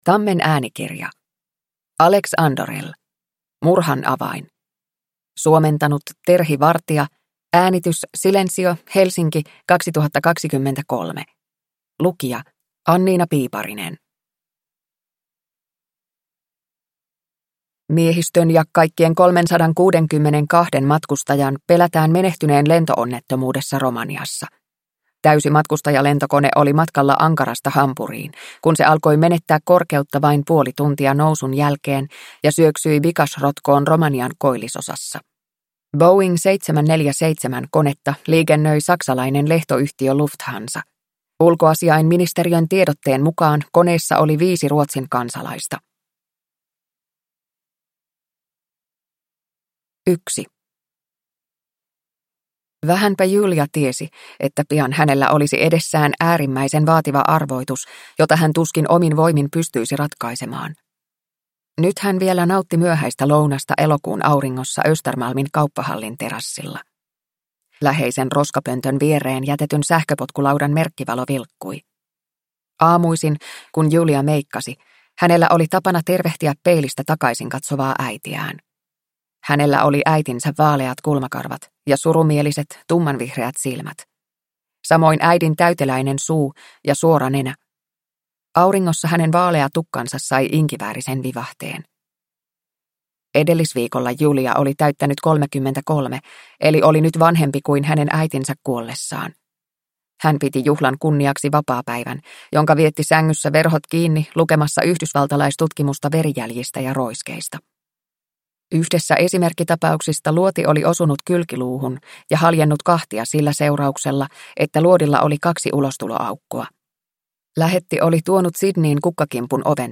Murhan avain – Ljudbok – Laddas ner